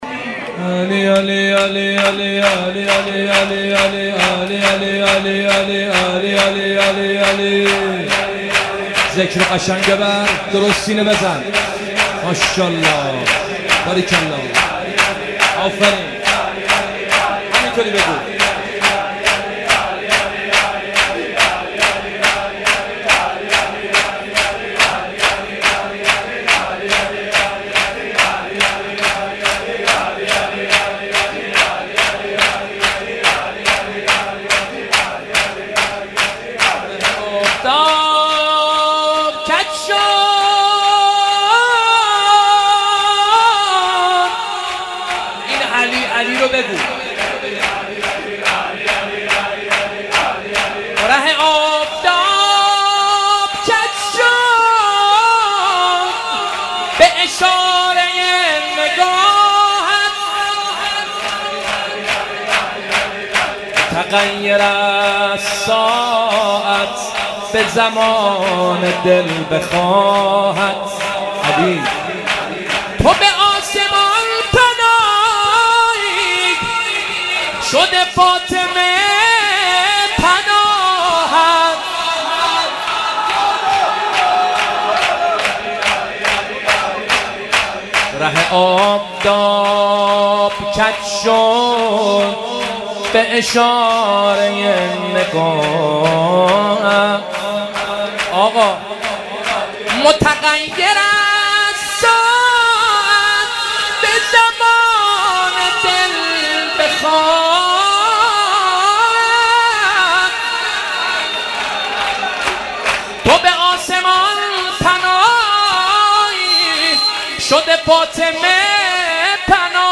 مناسبت : وفات حضرت زینب سلام‌الله‌علیها
قالب : شعر خوانی